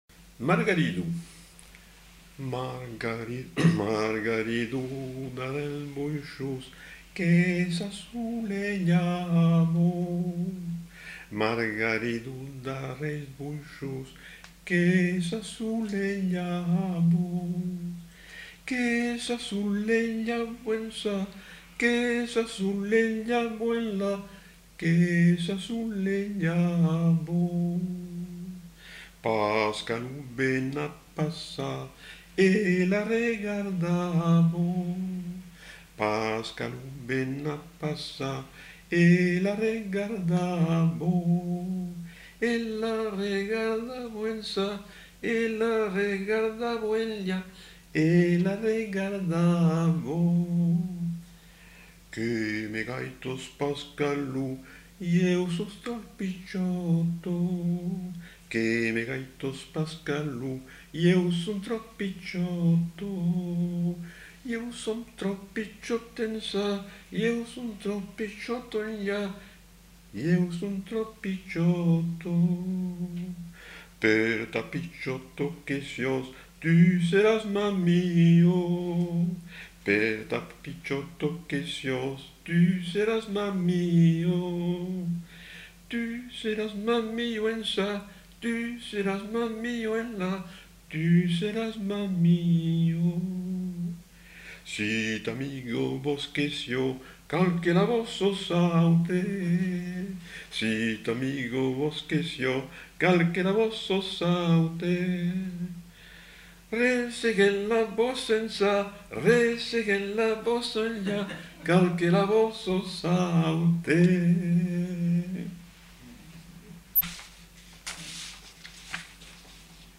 Aire culturelle : Quercorb
Lieu : Rivel
Genre : chant
Effectif : 1
Type de voix : voix d'homme
Production du son : chanté